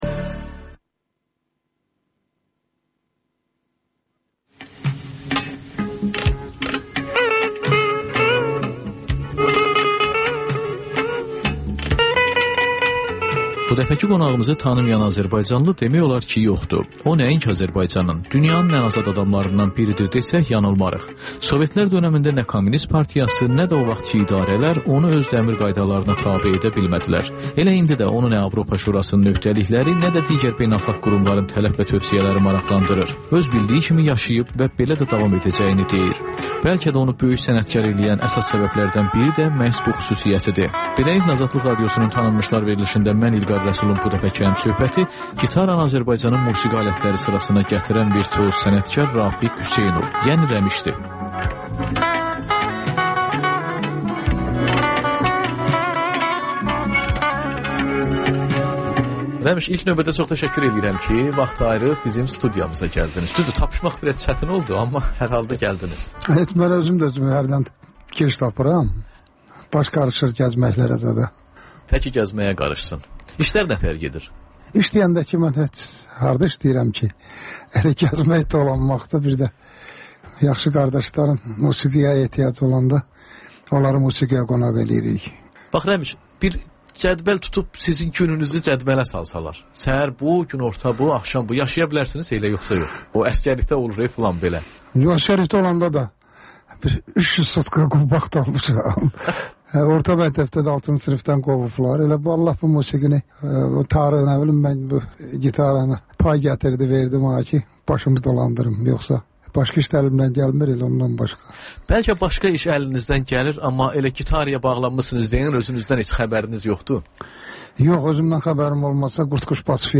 Ölkənin tanınmış simalarıyla söhbət (Təkrar)